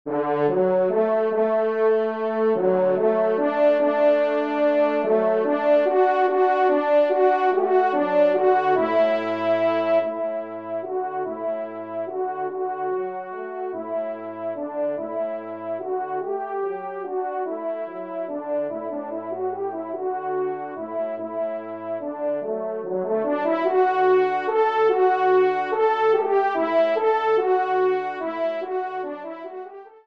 Genre :  Divertissement pour Trompes ou Cors en Ré
3e Trompe